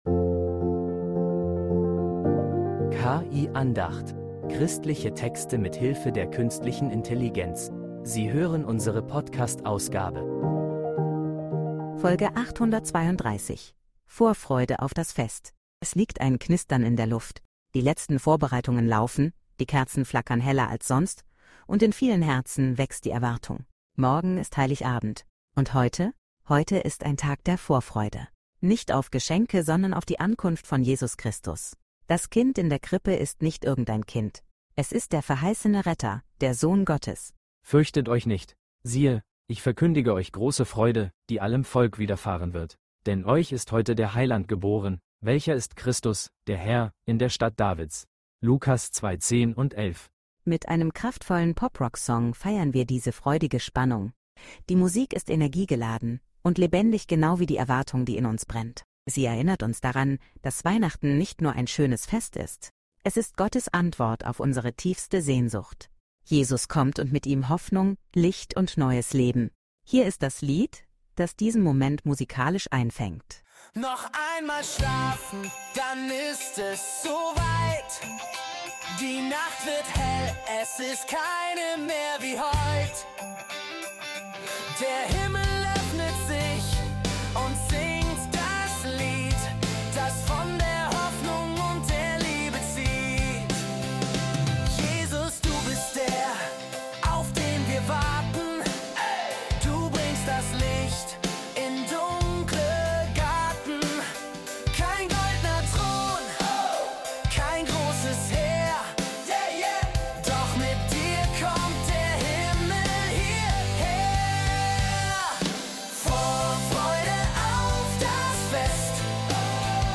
Lass dich von einem energiegeladenen Song